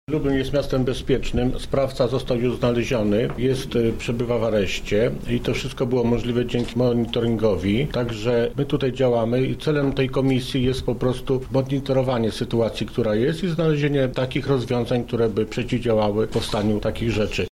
Obecną sytuację oceniamy jako dobrą – mówi Zbigniew Jurkowski, przewodniczący Komisji Samorządności i Porządku Publicznego: